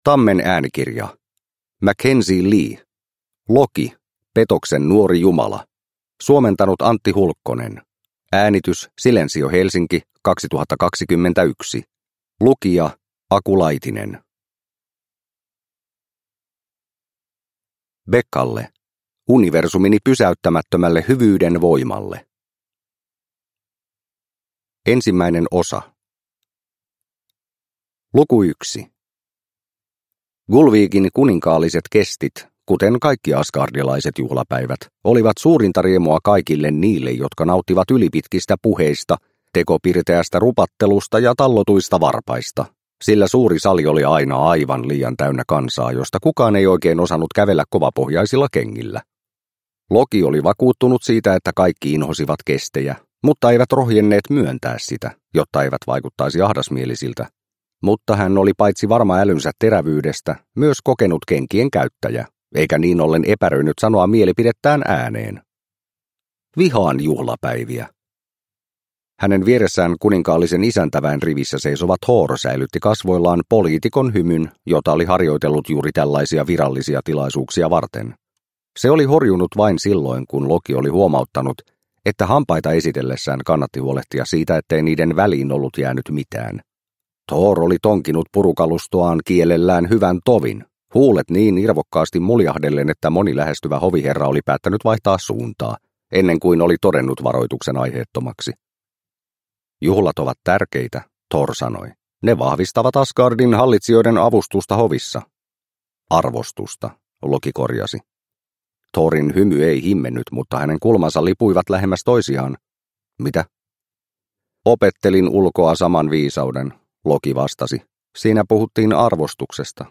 Loki - Petoksen nuori jumala – Ljudbok – Laddas ner